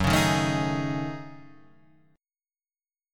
F# Minor 6th Add 9th